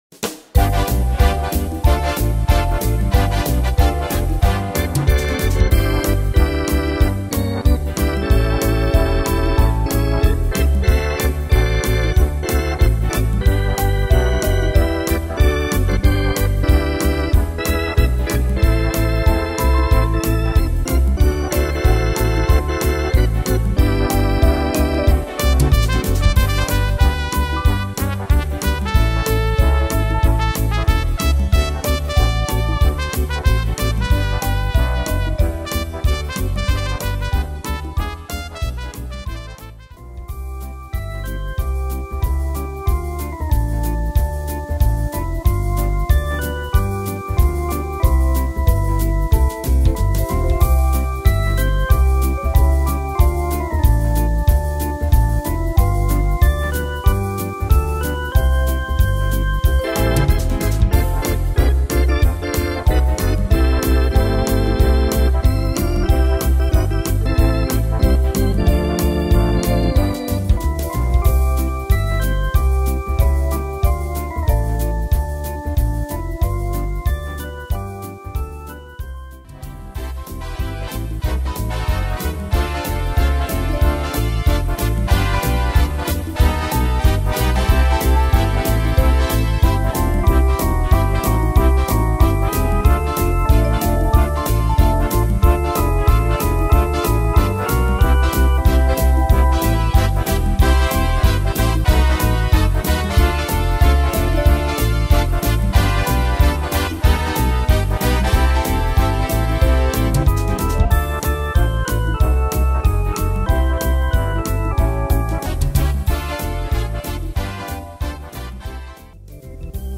Tempo: 186 / Tonart: G-Dur